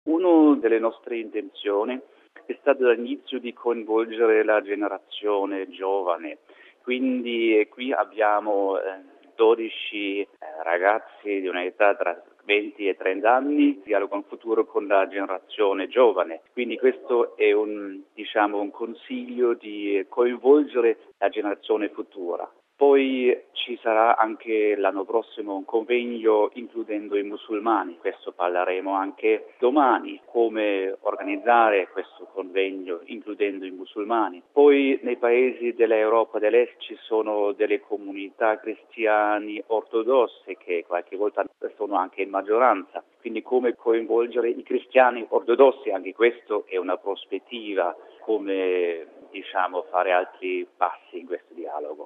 L'incontro di Budapest sta ponendo attenzione ai giovani per ciò che riguarda la loro formazione al dialogo interreligioso. Lo conferma al microfono di